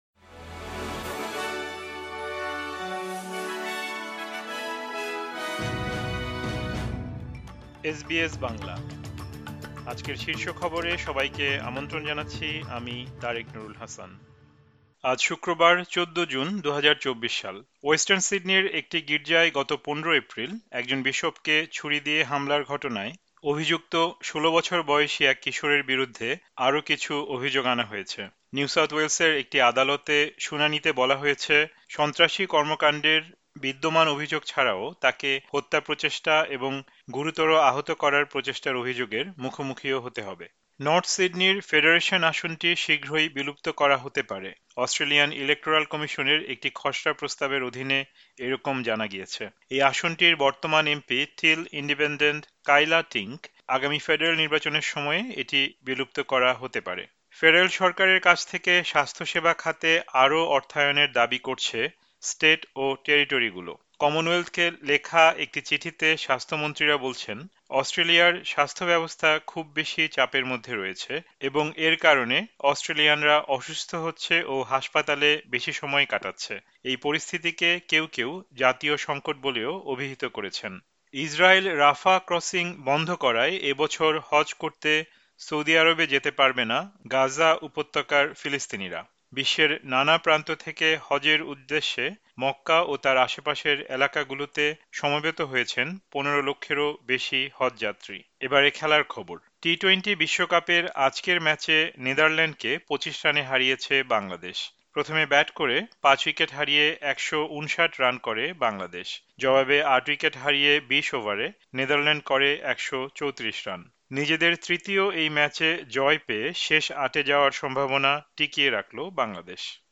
এসবিএস বাংলা শীর্ষ খবর: ১৪ জুন, ২০২৪